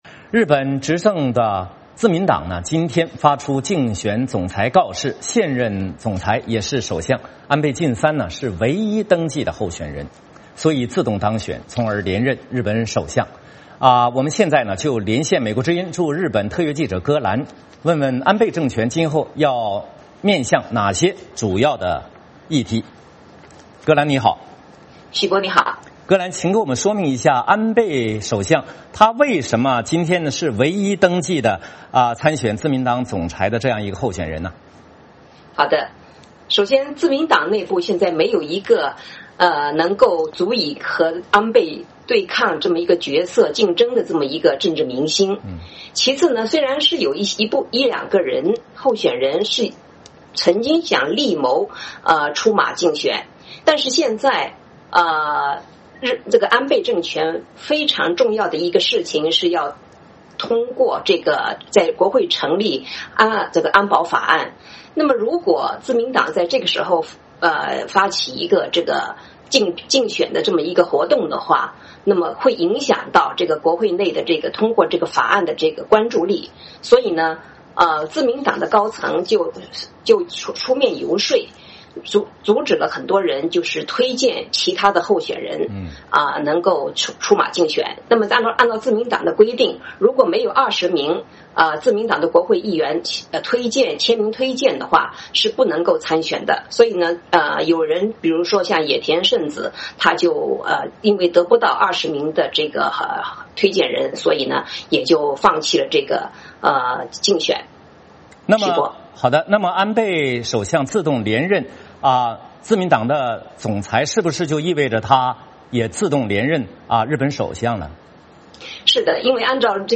VOA连线：安倍晋三自动连任日本首相